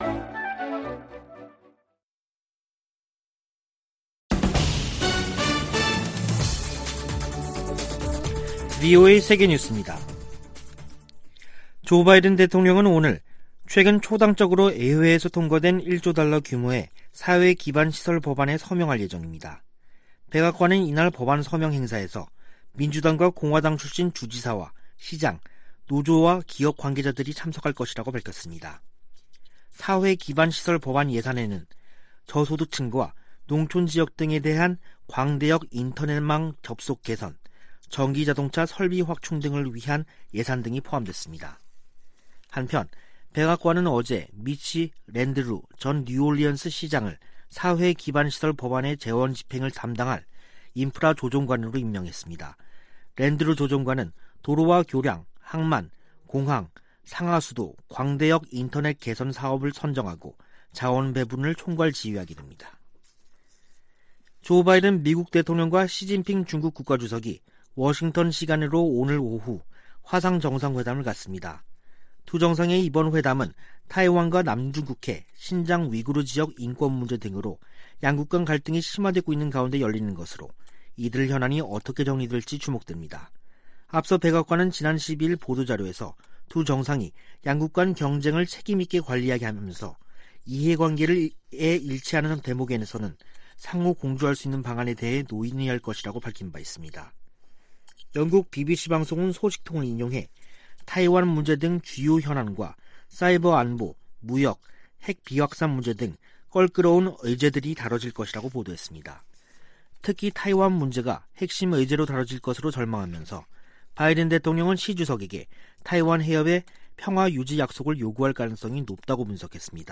VOA 한국어 간판 뉴스 프로그램 '뉴스 투데이', 2021년 11월 15일 3부 방송입니다. 미국과 한국 사이에 종전선언 논의가 이어지는 가운데 북한이 유엔군사령부 해체를 연이어 주장하고 있습니다. 미 국무부 동아시아태평양 담당 차관보는 미국이 북한에 전제조건 없는 대화 제안을 했으며, 북한의 답을 기다리고 있다고 말했습니다.